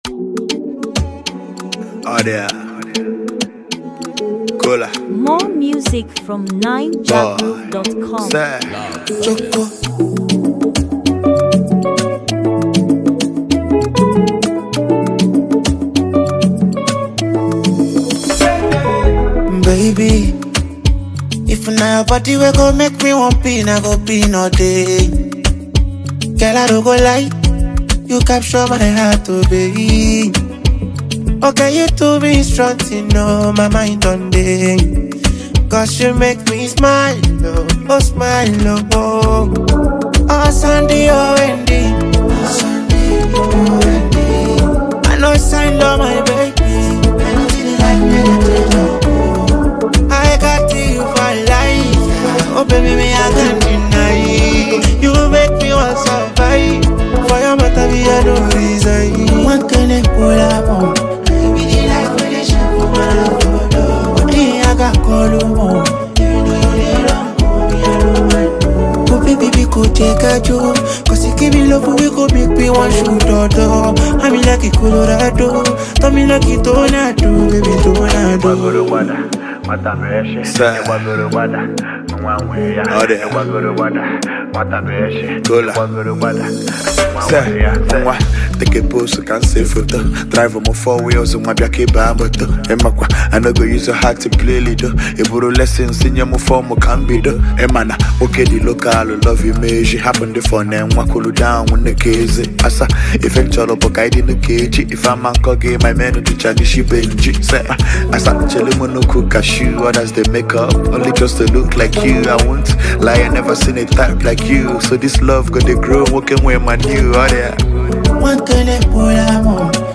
Latest, Naija-music
afro soul-beat